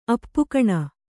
♪ appukaṇa